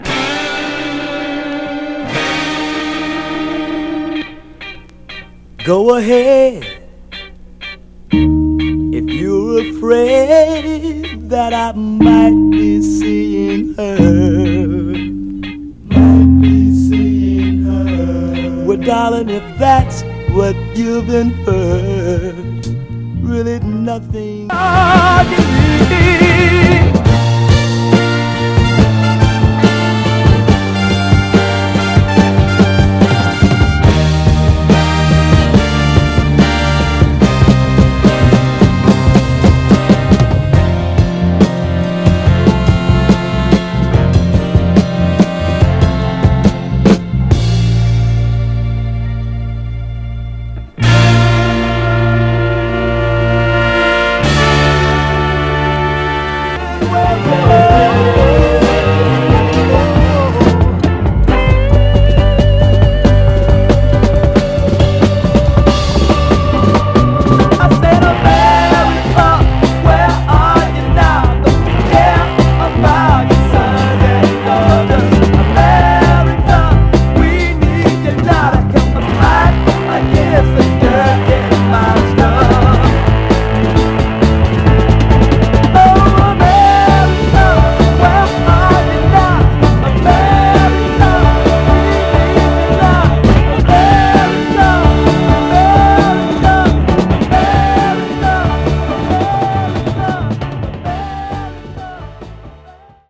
シカゴ産兄弟グループ代表
ファンクなホーンと哀愁ヴォーカルで緩急つけて展開する、クロスオーヴァーなメロウ・シカゴ・ソウルです！
※試聴音源は実際にお送りする商品から録音したものです※